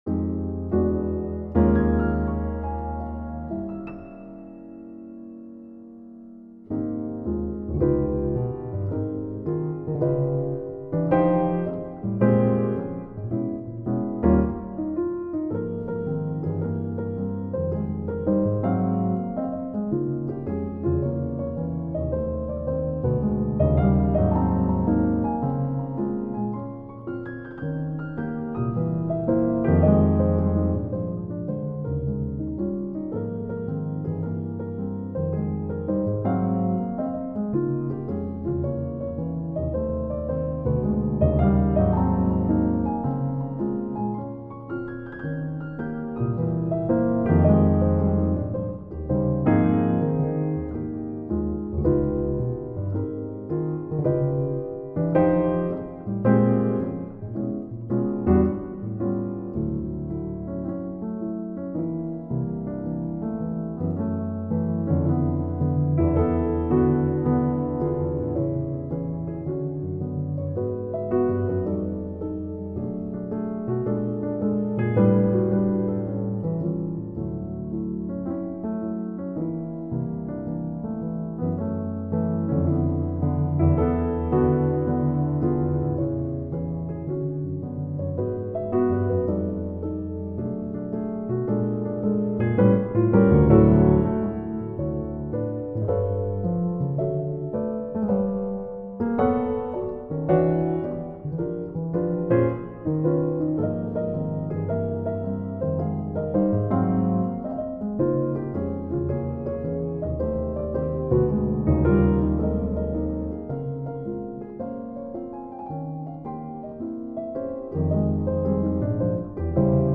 in Piano Music, Solo Keyboard
A few days ago, I wrote a very short piano work for my brother's birthday, which is not something I normally do.